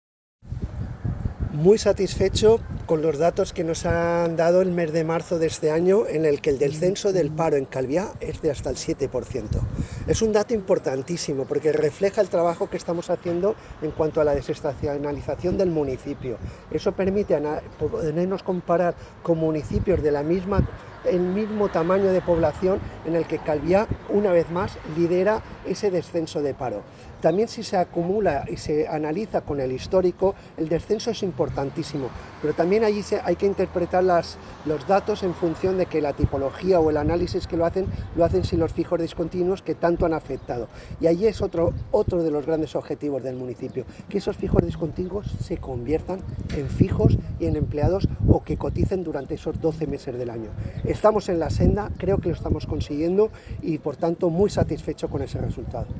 declaraciones-alcalde-juan-antonio-amengual.m4a